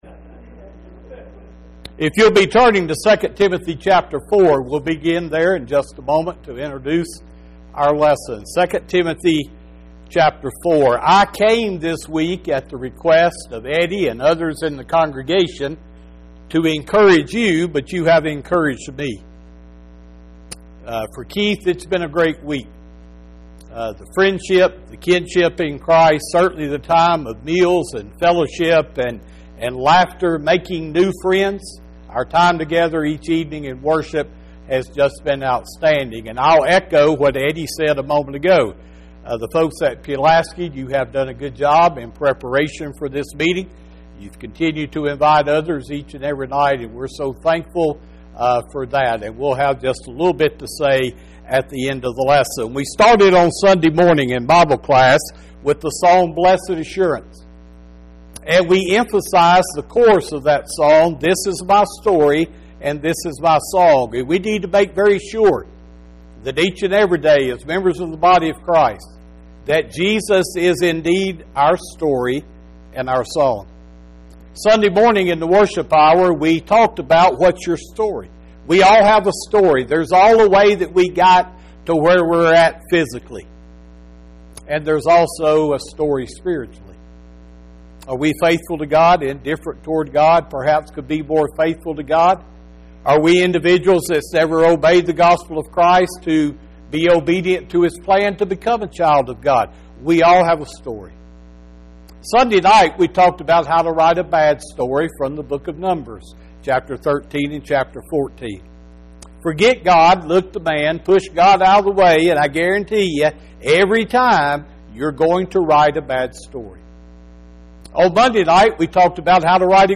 When Your Story Ends – Gospel Meeting